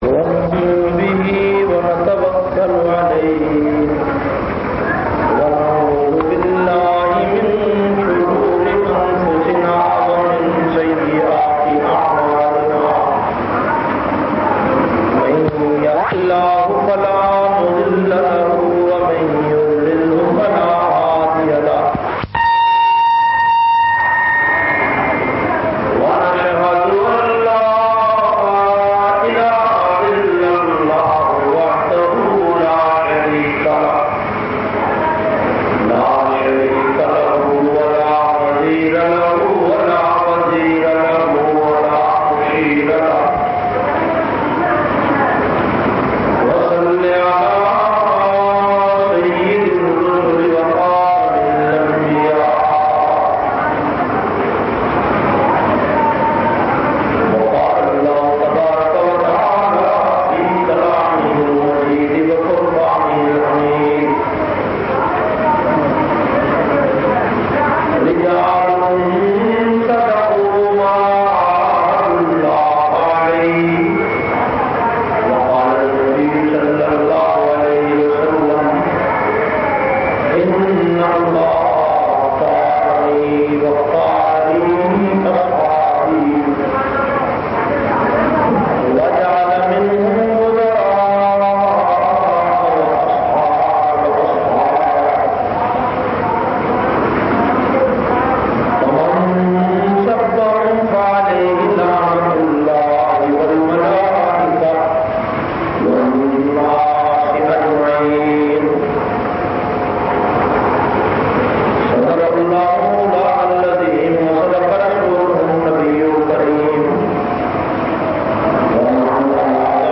473- Shan e Sahaba Jumma khutba Jamia Masjid Muhammadia Samandri Faisalabad.mp3